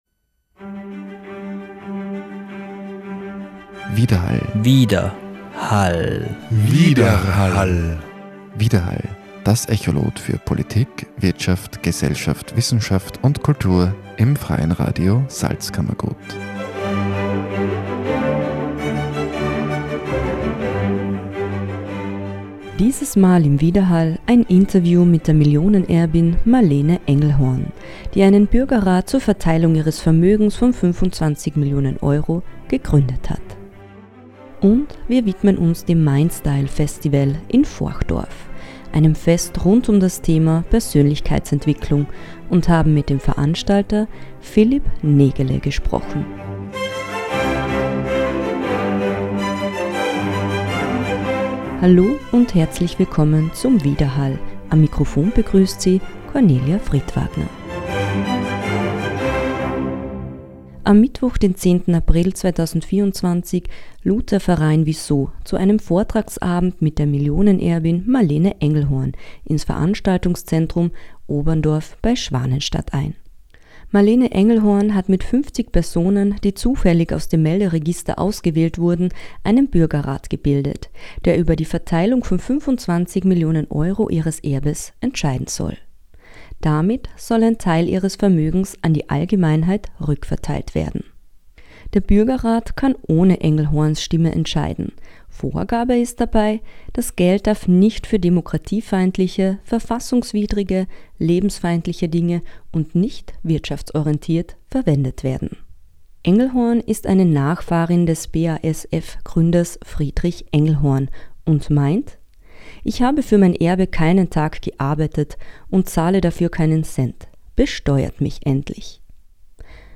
„Besteuert mich endlich!“ – Interview mit Marlene Engelhorn